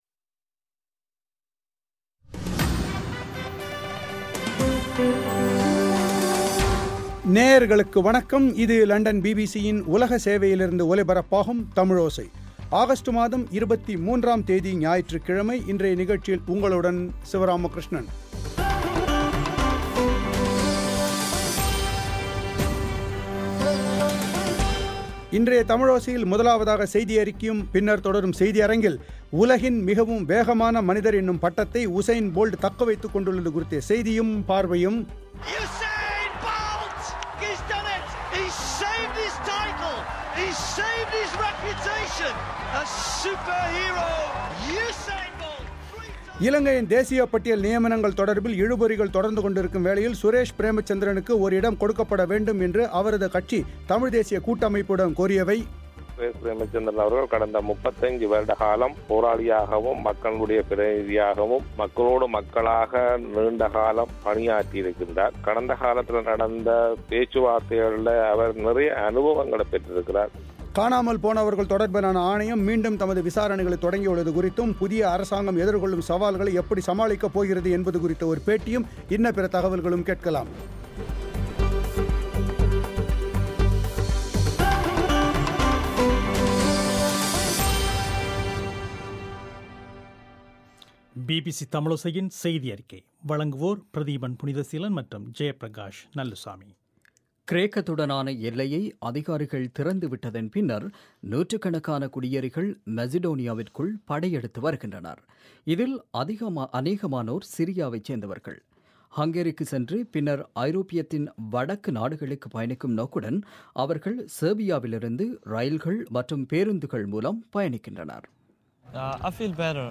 புதிய இலங்கை அரசு எதிர்கொள்ளும் சவால்களை எப்படி சமாளிக்கப் போகிறது என்பது குறித்து அரச தரப்பு என்ன கருதுகிறது எனபது பற்றி ஒரு பேட்டி.